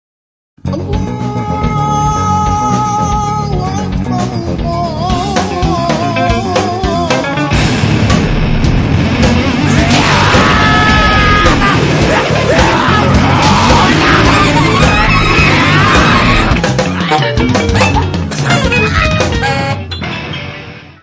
saxofone alto, clarinete
bateria
guitarra
teclados
Área:  Novas Linguagens Musicais